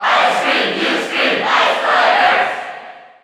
File:Ice Climbers Cheer English SSBU.ogg
Crowd cheers (SSBU) You cannot overwrite this file.
Ice_Climbers_Cheer_English_SSBU.ogg